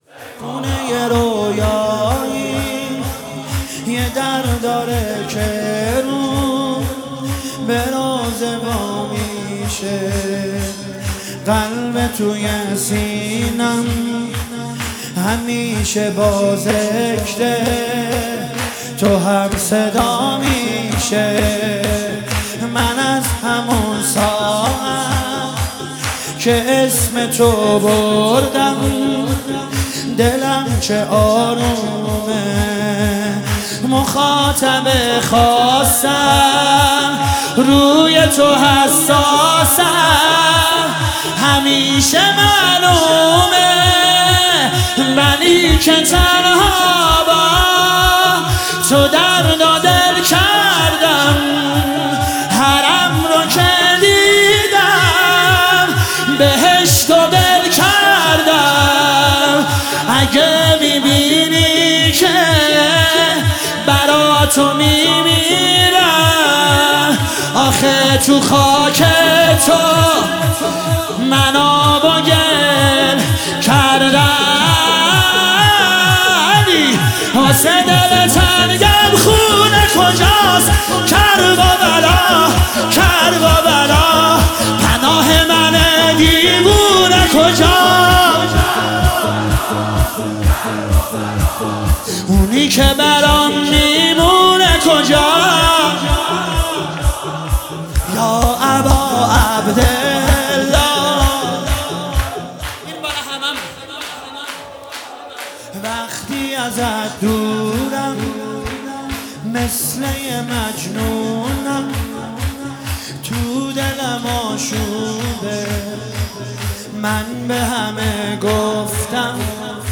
مداحی به سبک شور اجرا شده است.